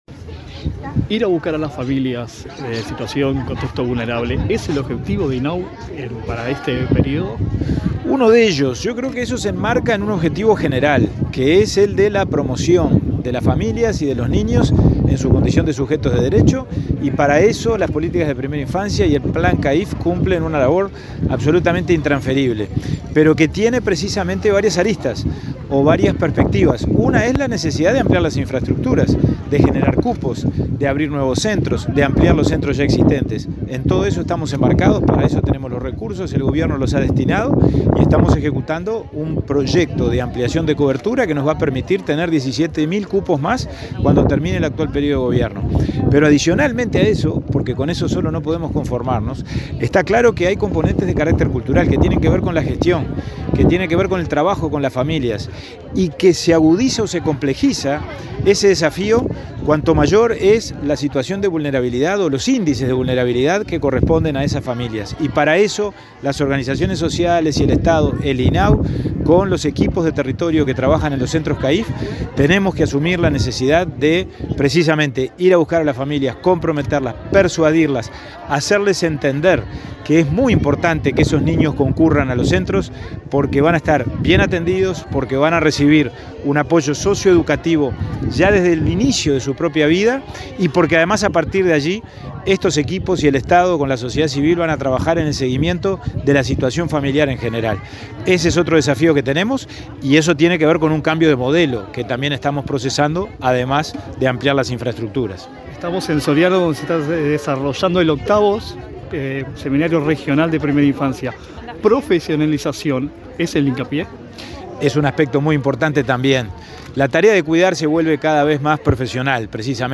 Entrevista al presidente del INAU, Pablo Abdala